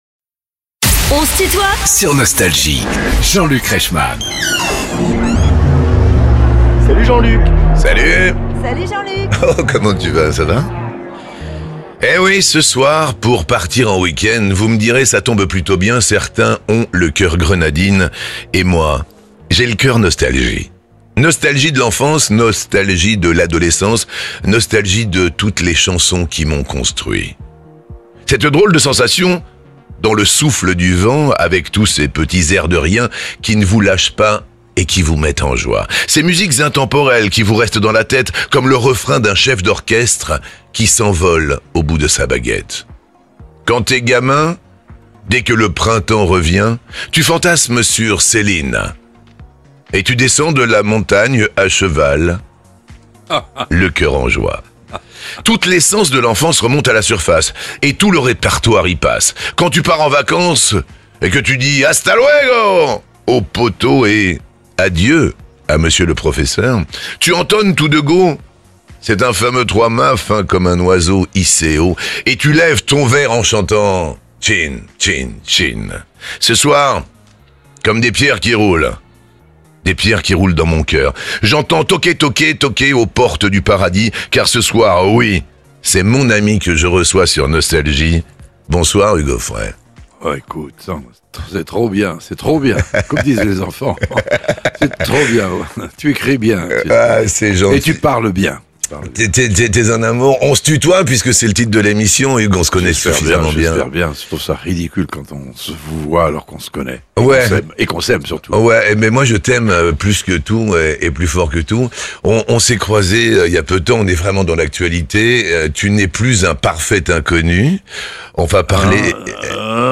Hugues Aufray doit sa fructueuse carrière au hasard de rencontres déterminantes. Invité de "On se tutoie ?..." avec Jean-Luc Reichmann, il nous raconte